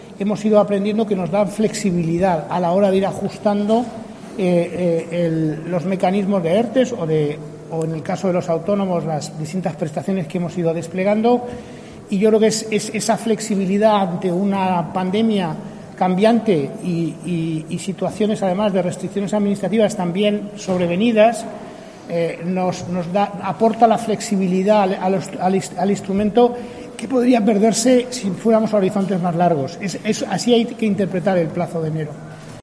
José Luis Escrivá ha afirmado en una rueda de prensa junto al presidente de Canarias, Ángel Víctor Torres, que es consciente "obviamente" de la ultraperificidad del archipiélago pero, en su opinión, el diseño de los ERTE tiene suficientes elementos para recoger las demandas de las islas sin tener que aludir a elementos "particulares".